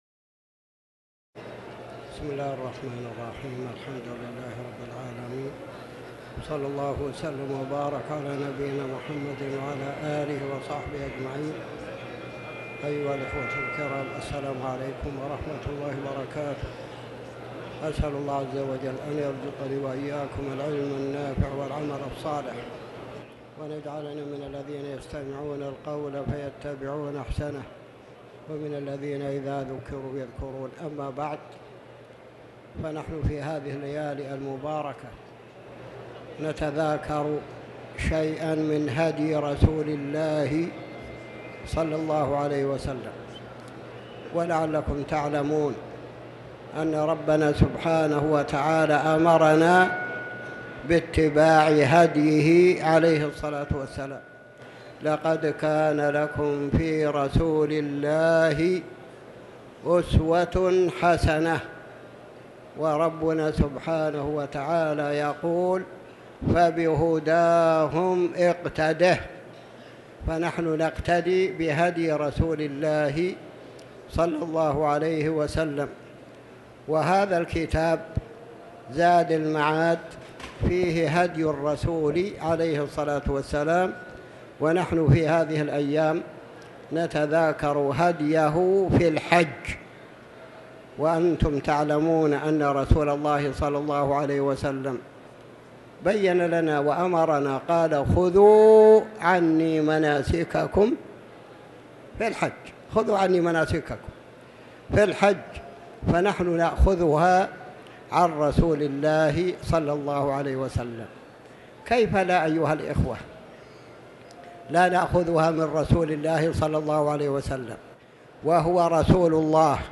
تاريخ النشر ٦ جمادى الأولى ١٤٤٠ هـ المكان: المسجد الحرام الشيخ